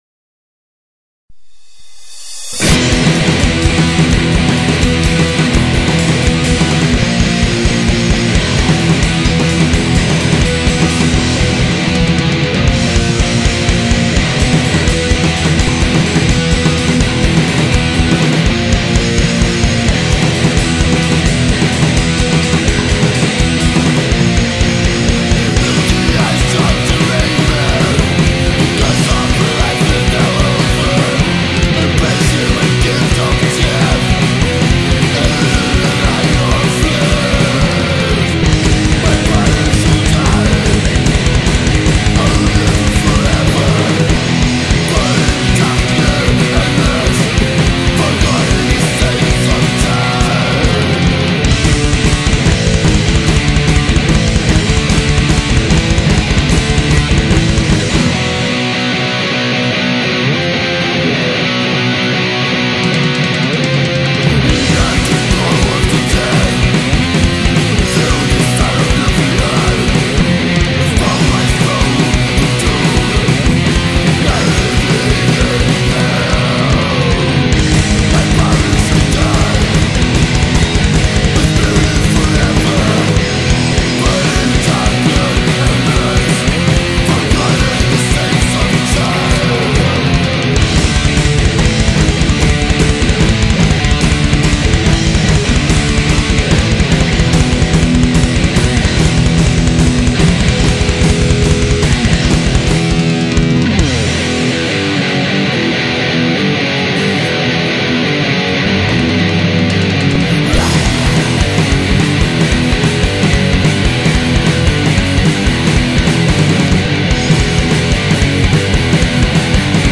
old school metal
Old-school Death Metal